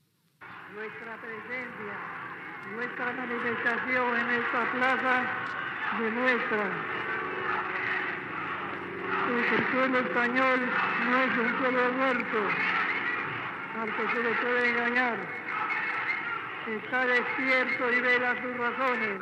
Fragment de l'últim discurs públic del "generalisimo" Francisco Franco, a la plaça d'Oriente de Madrid, el Dia del Caudillo
Informatiu